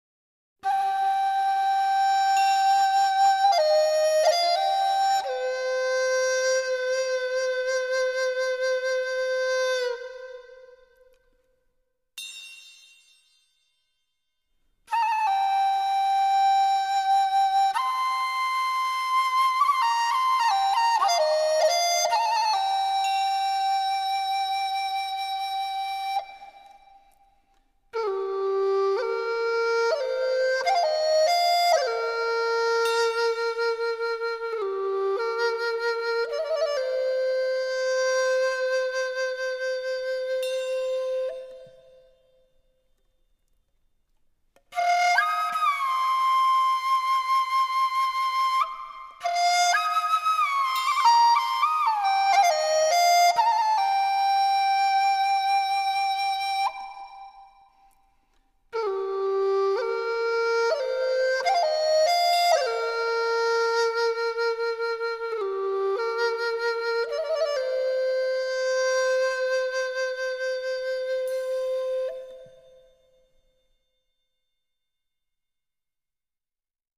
合成乐器与和太鼓的绝妙融合
这是一个融合合成乐器与和太鼓的绝妙途径。
和太鼓在这里的角色就是建立一种稳定的动力感。
这些有速度感的乐句把音乐一浪又一浪的推向前。